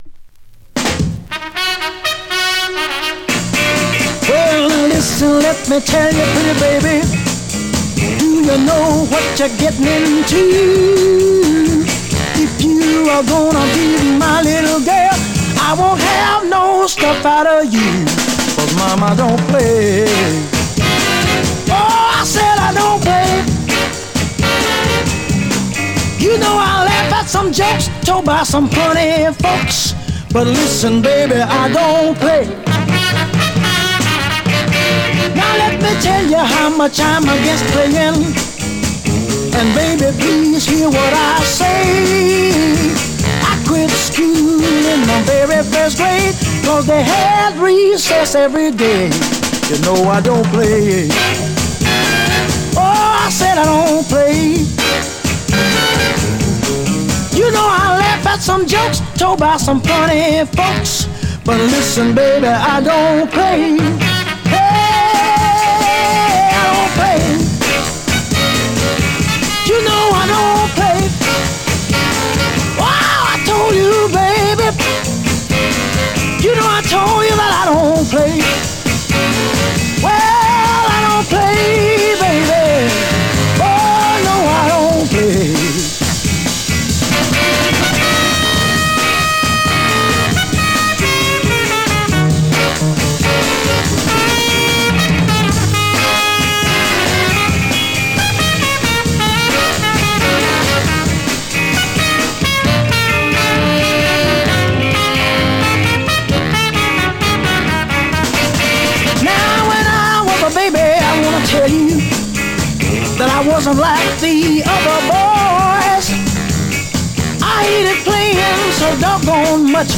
Vinyl has a few light marks plays great .
Category: R&B, MOD, POPCORN